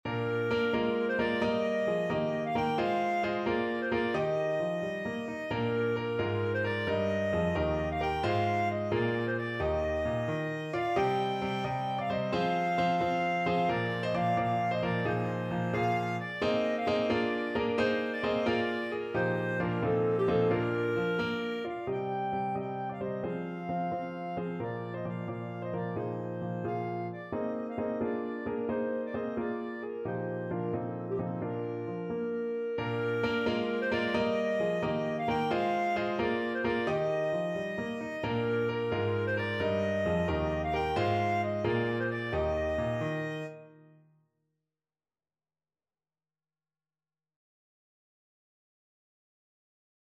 6/8 (View more 6/8 Music)
Traditional (View more Traditional Clarinet Music)